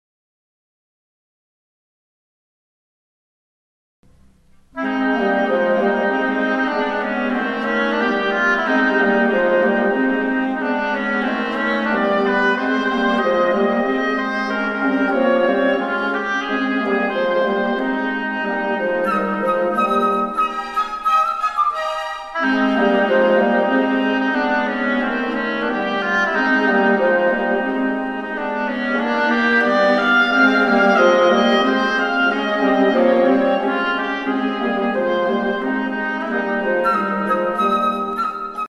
Hobo